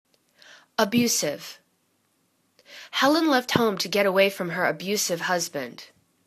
a.bu.sive     /ə'bju:siv/    adj